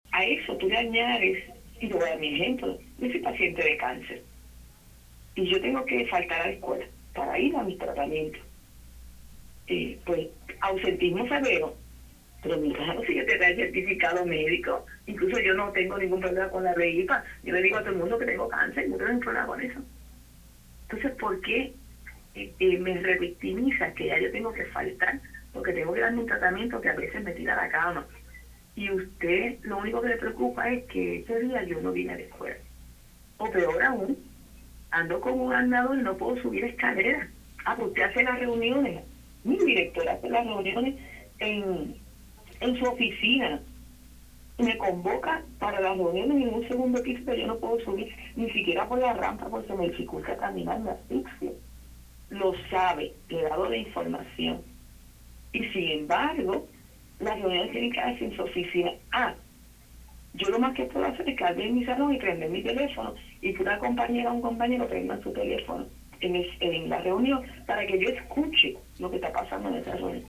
en entrevista con Radio Isla